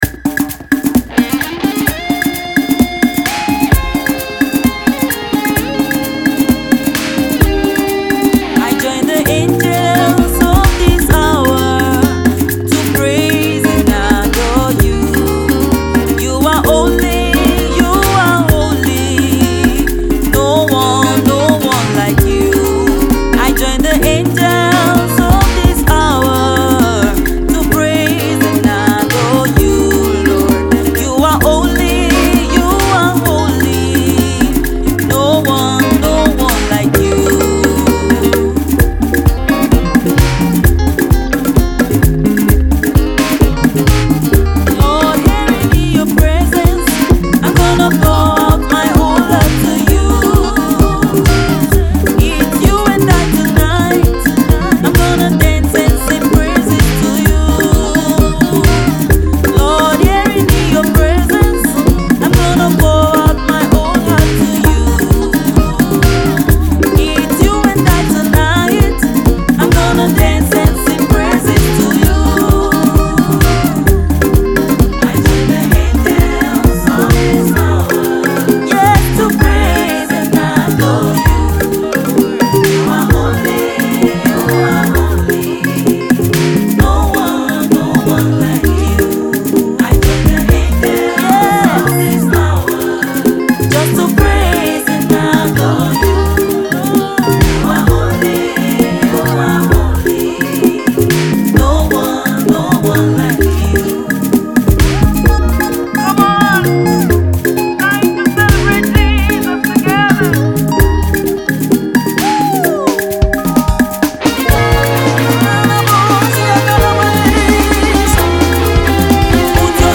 a very powerful HOLY GHOST drive praise groove song.